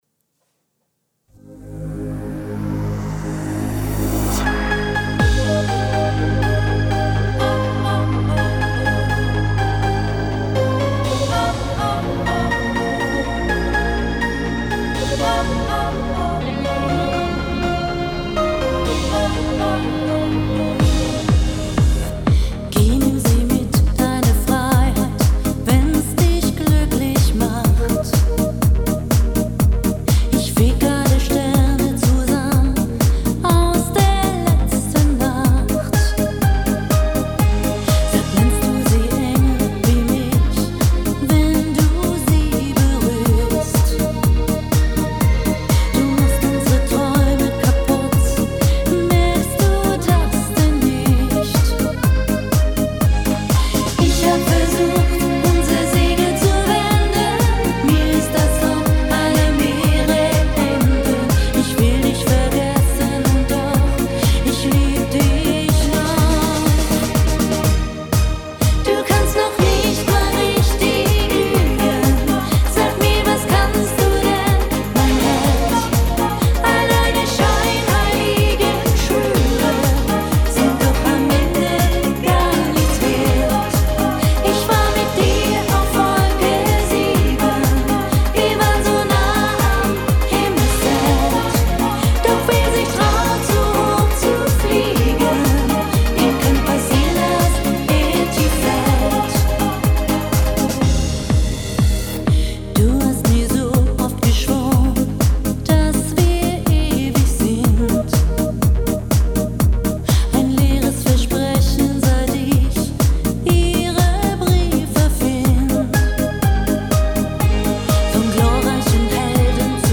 • Sänger/in